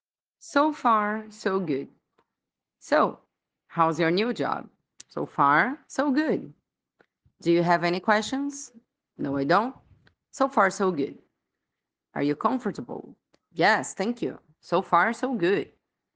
Attention to pronunciation 🗣🗣🗣